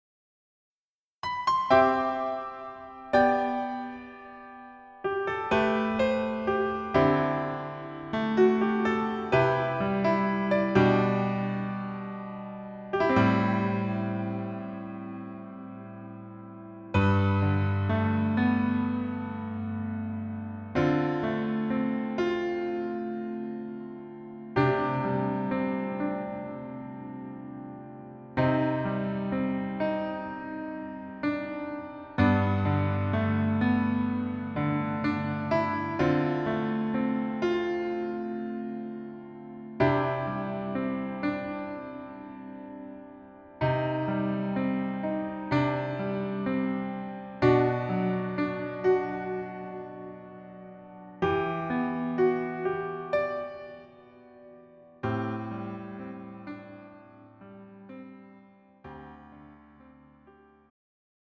음정 원키
장르 가요 구분 Pro MR